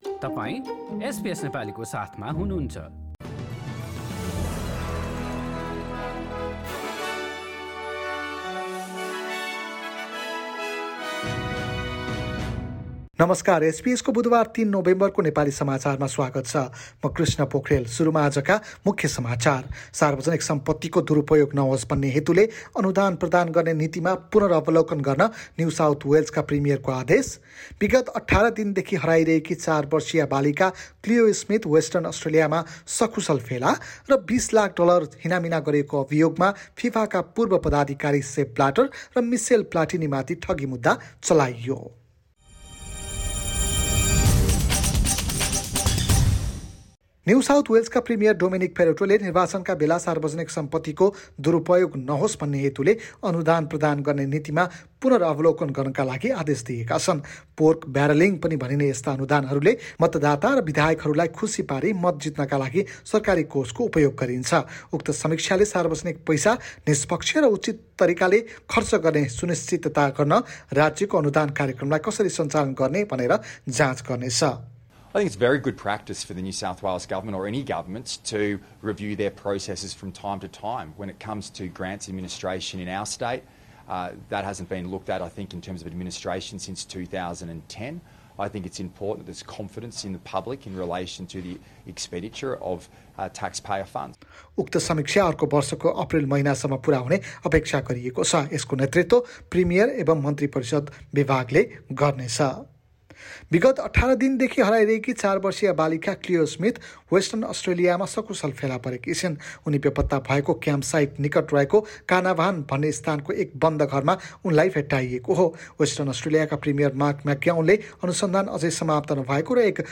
एसबीएस नेपाली अस्ट्रेलिया समाचार: बुधवार ३ नोभेम्बर २०२१